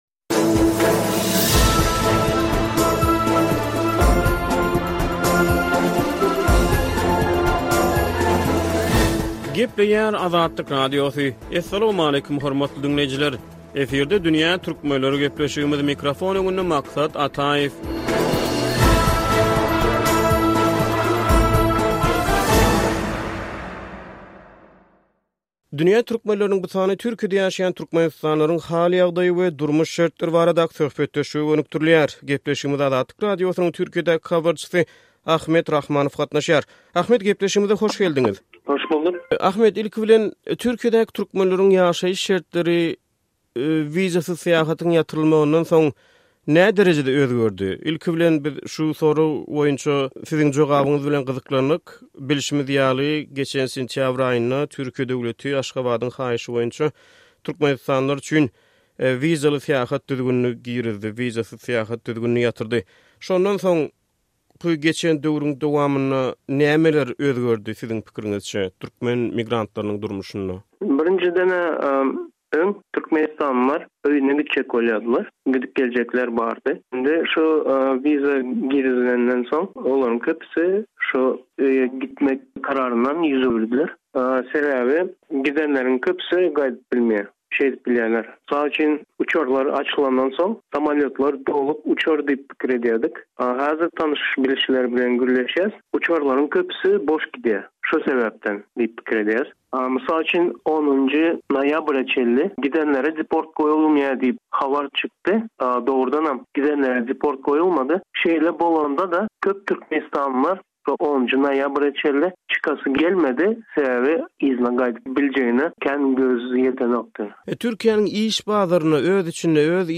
Dünýä Türkmenleriniň bu sany Türkiýede ýaşaýan türkmenistanlylaryň durmuş şertleri baradaky söhbetdeşlige gönükdirilýär.